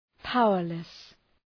Προφορά
{‘paʋərlıs}